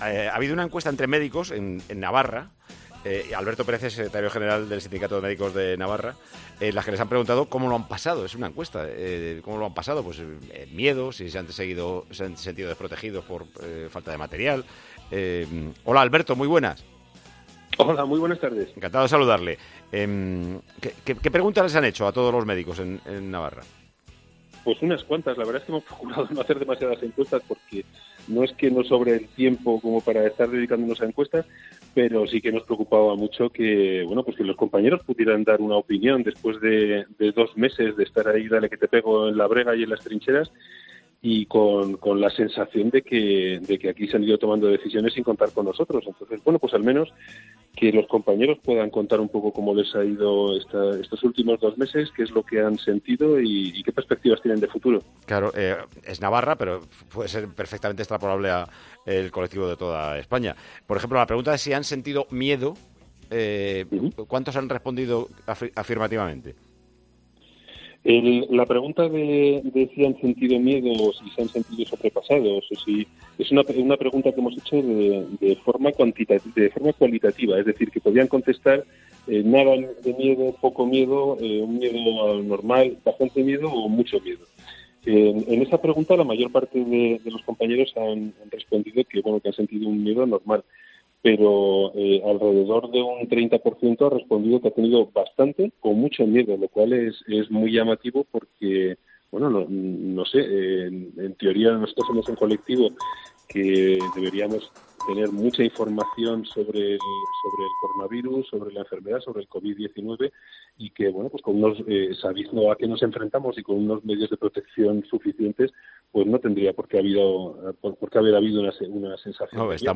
Paco González charló este domingo en Tiempo de Juego